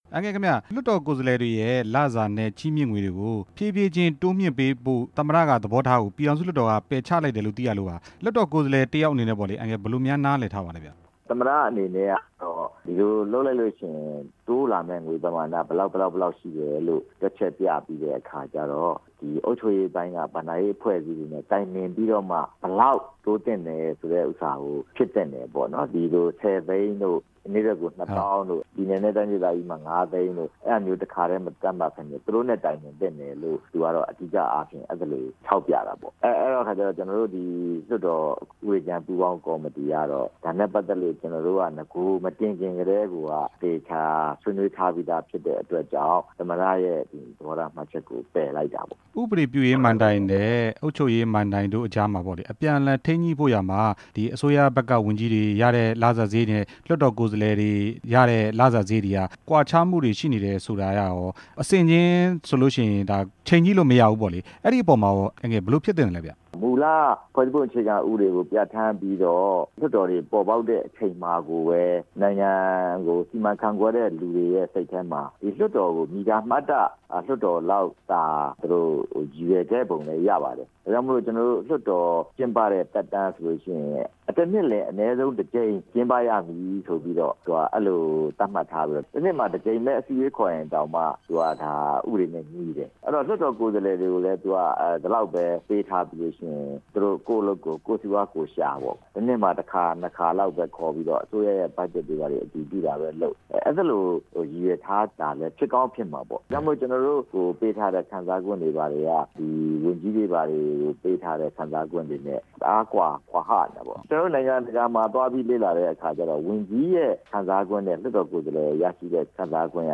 လွှတ်တော်ကိုယ်စားလှယ်တွေ တိုးမြှင့်လစာခံစားခွင့်ရှိမှာဖြစ်တဲ့ အကြောင်းမေးမြန်းချက်